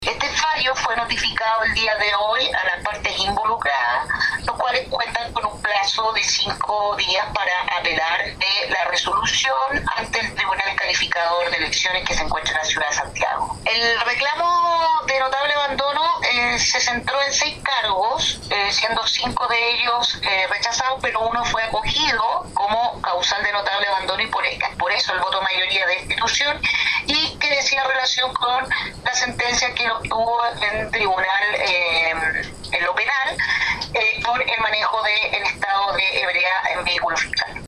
La abogada, además, señaló que, el fallo ya fue notificado a los involucrados, quienes cuentan con un plazo para apelar a la sanción: